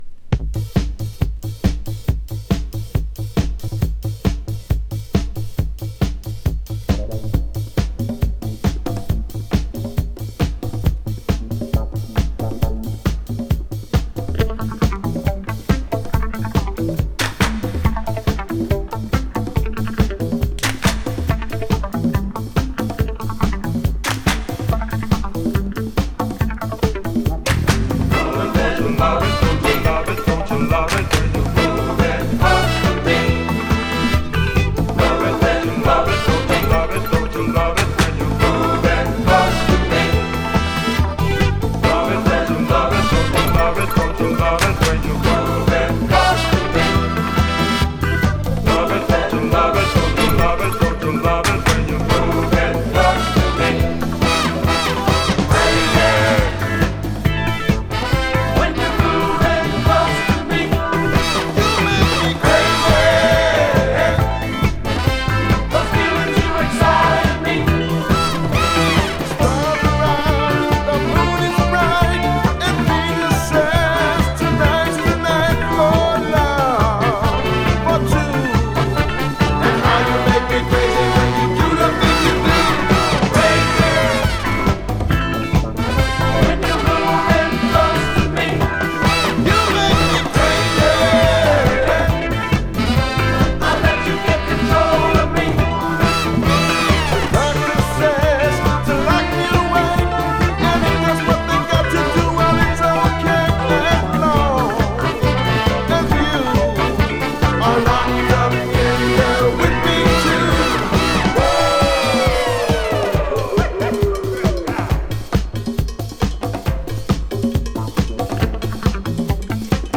[HI-NRG] [DISCO]